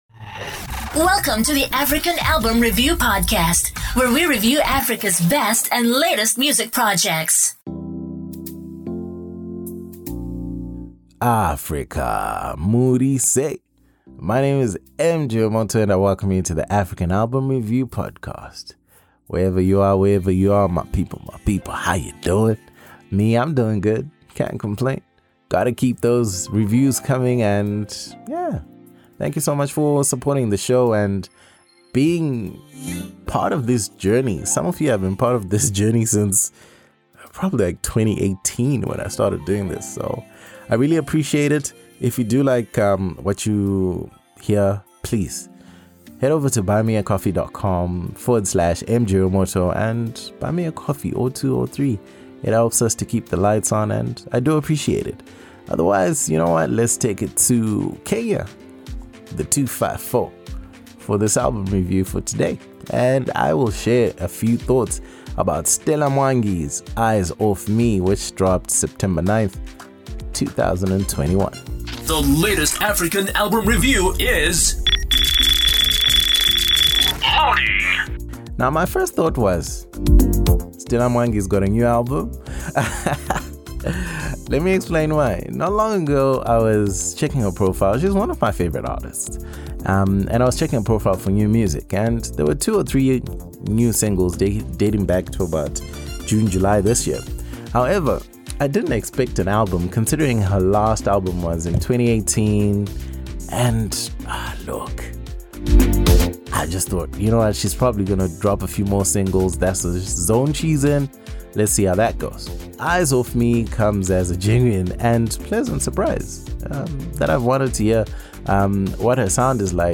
Music commentary and analysis on African albums